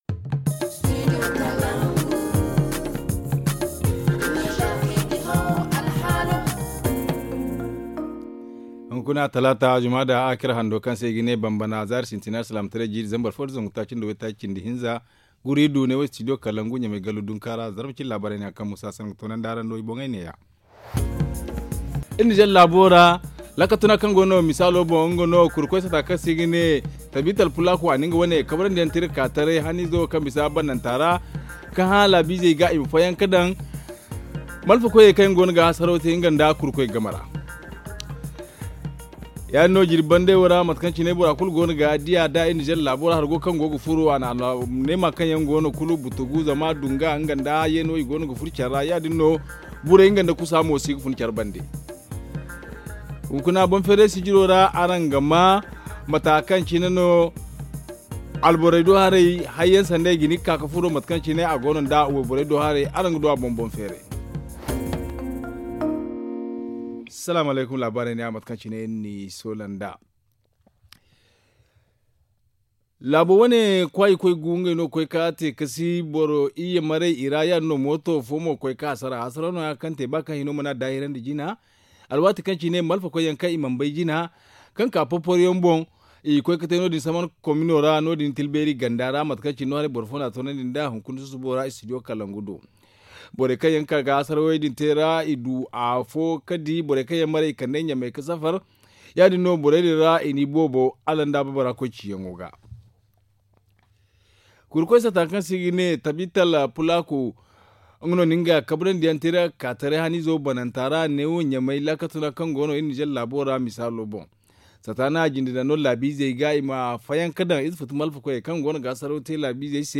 Le journal du 4 janvier 2021 - Studio Kalangou - Au rythme du Niger